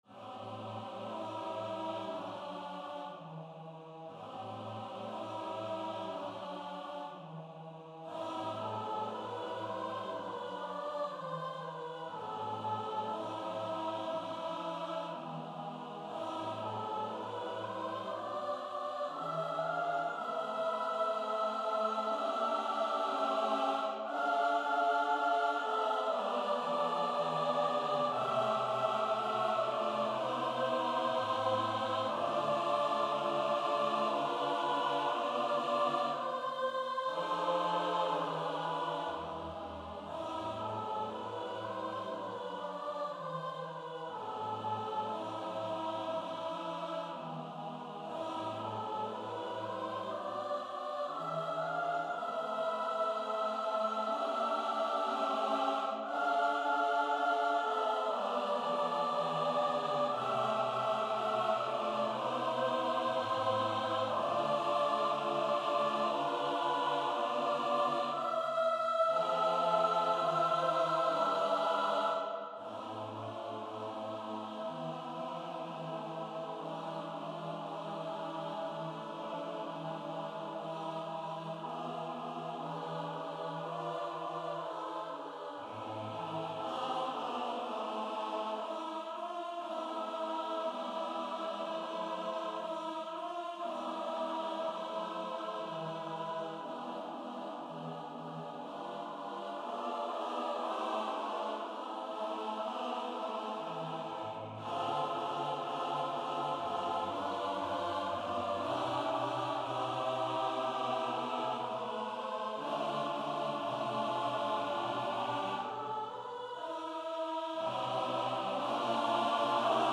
for a-capela SATB Choir